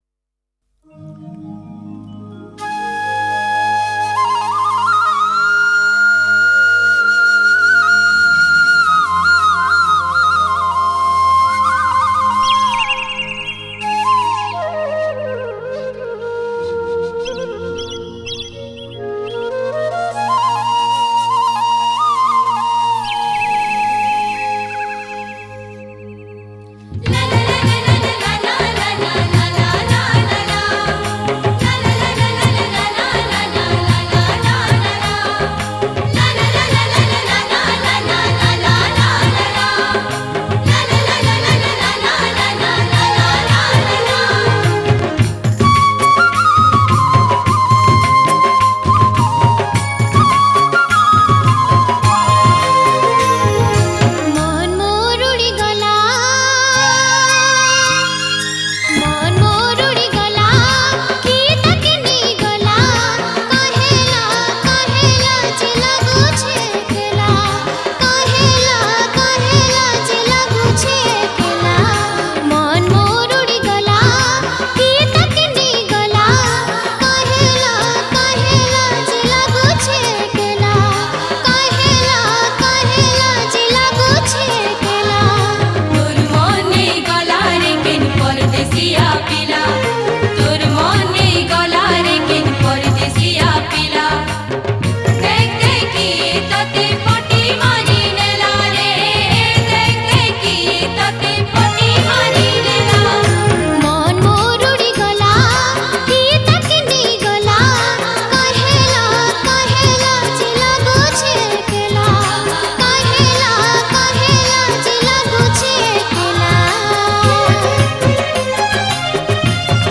New Sambalpuri Song 2025